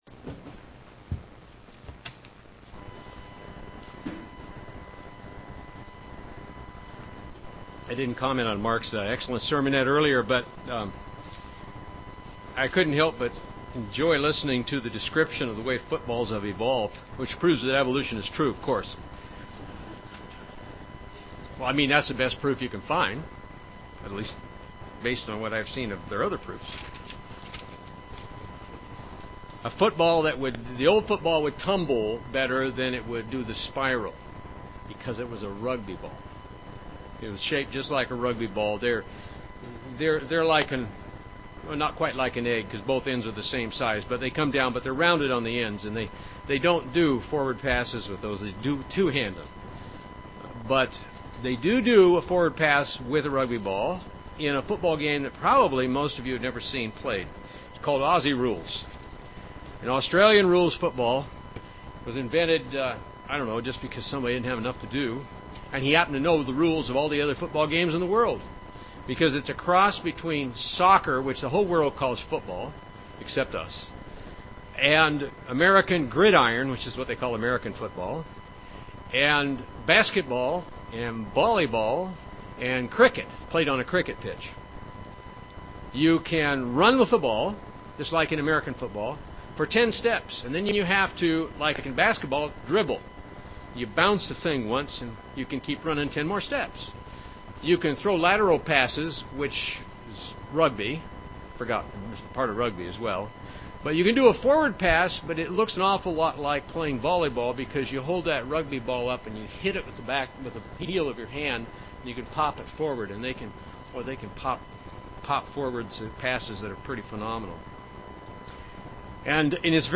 Good vs Righteousness UCG Sermon Studying the bible?